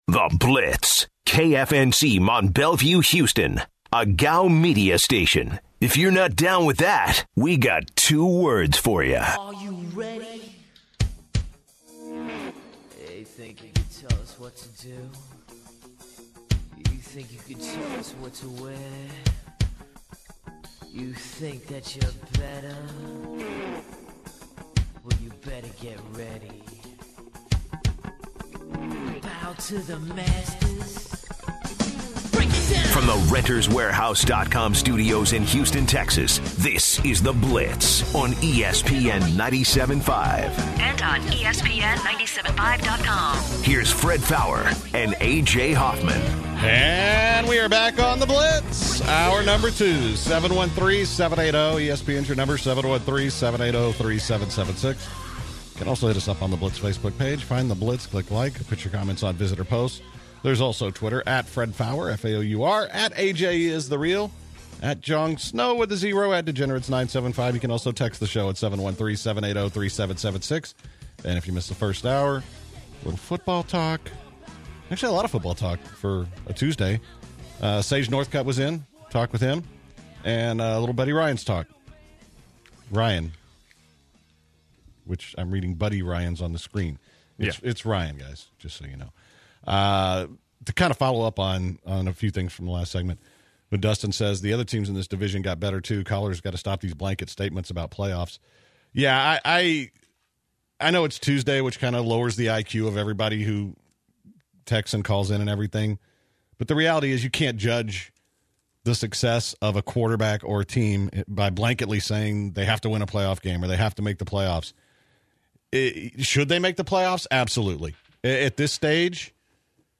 the Blitzers call in to give their take of what they think and if it crosses the line.